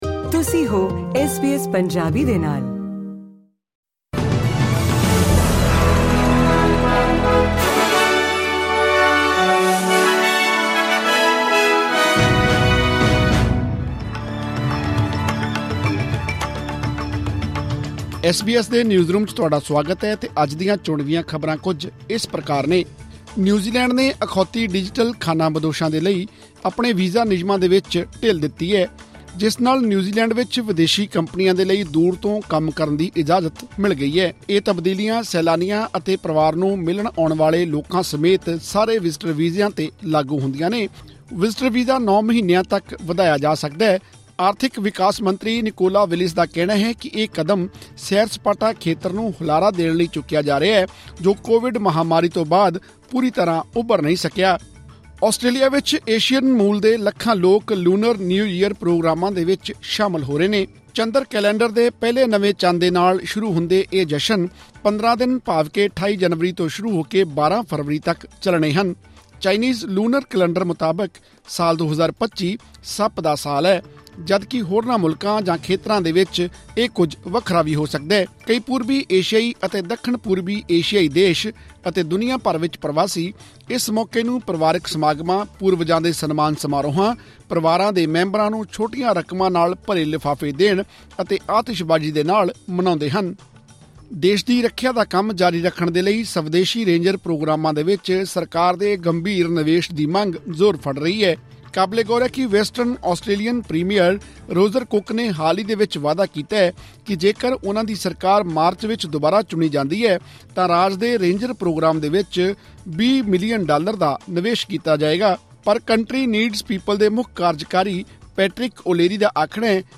ਖ਼ਬਰਨਾਮਾ : ਨਿਊਜ਼ੀਲੈਂਡ ਨੇ ਵੀਜਾ ਨਿਯਮਾਂ ਵਿੱਚ ਦਿੱਤੀ ਢਿੱਲ